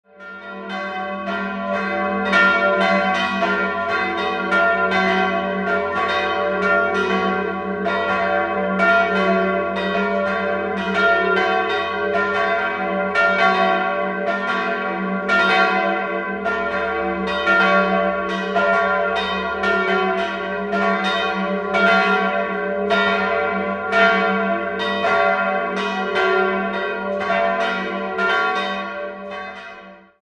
Die große Glocke wurde 1883 von Josef Anton Spannagl in Regensburg gegossen, die mittlere stammt von Johann Hahn (Landshut) aus dem Jahr 1950 und die kleine ist ein Werk von Karl Hamm (Regensburg) aus dem Jahr 1924.